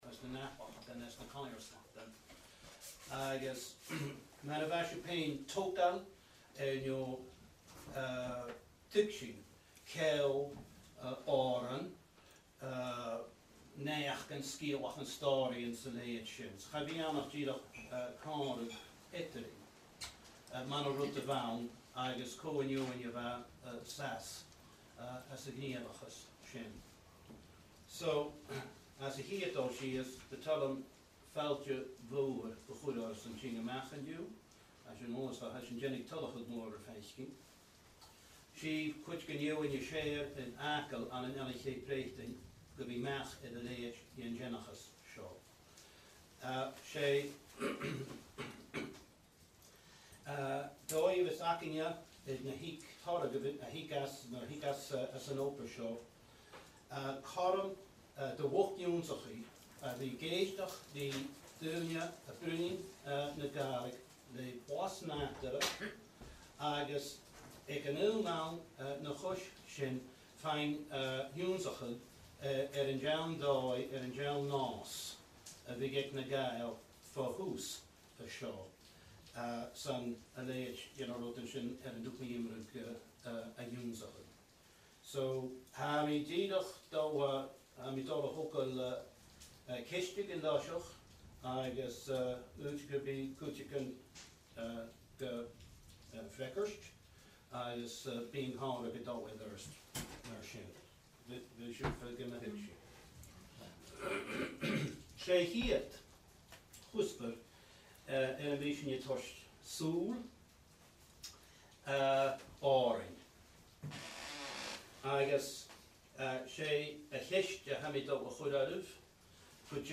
An Clachan Gàidhealach, Sanndraigh
Agallamh